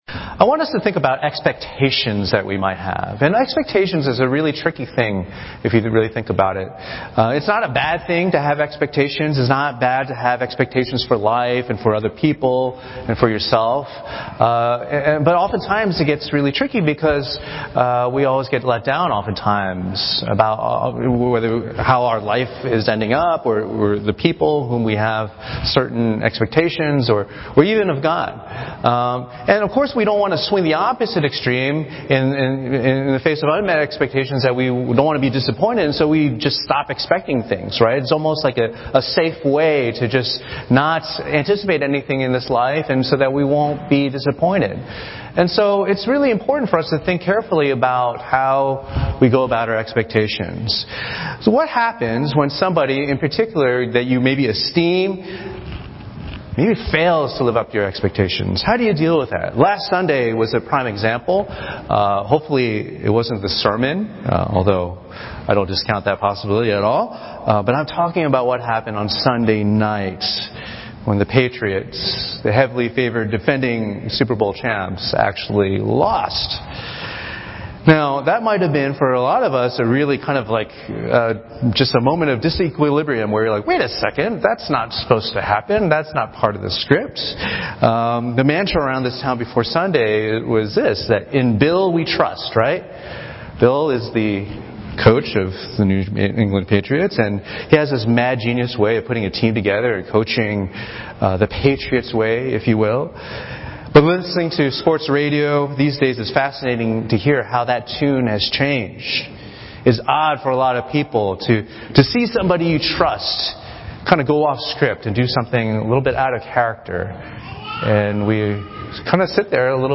Sermons - Page 26 of 74 | Boston Chinese Evangelical Church